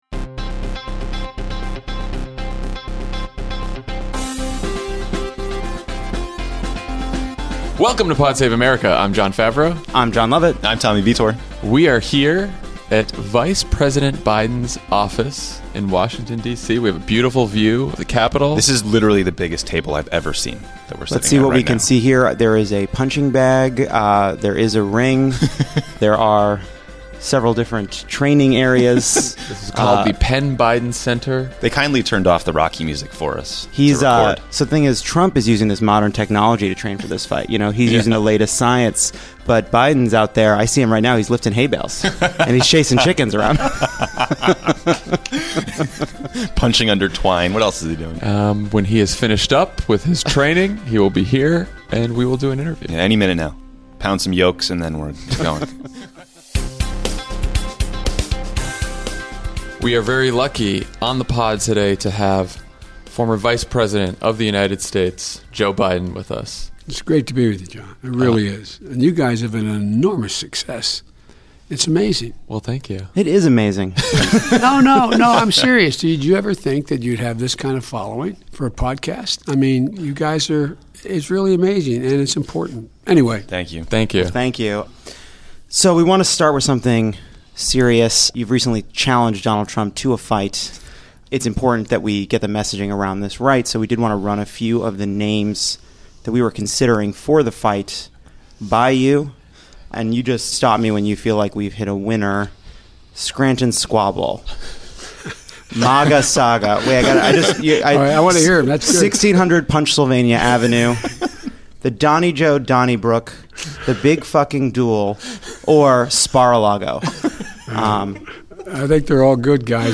Vice President Joe Biden sits down with Jon, Jon, and Tommy at his office in Washington, DC to talk about Donald Trump, John Bolton, partisanship, the economy, and the Biden Cancer Initiative.